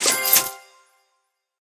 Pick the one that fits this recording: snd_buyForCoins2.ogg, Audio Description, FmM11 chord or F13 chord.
snd_buyForCoins2.ogg